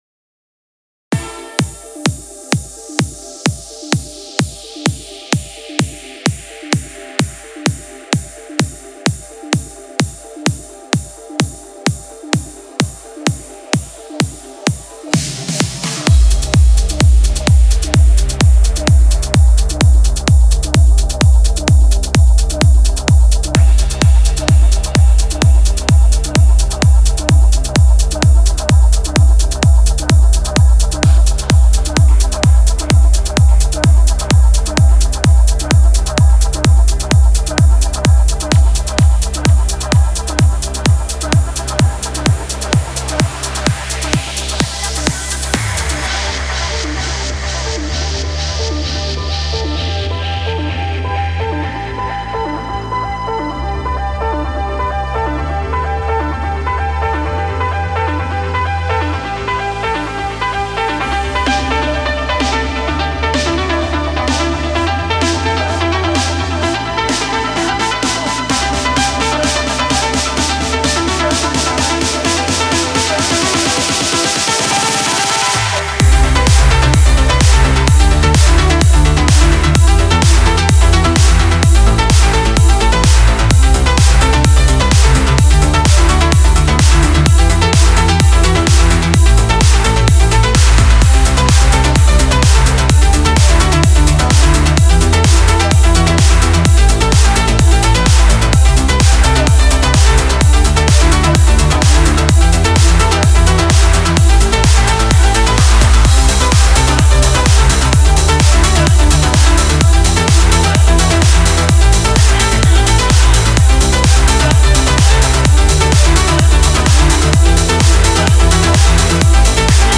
Стиль: Progressive House / Progressive Trance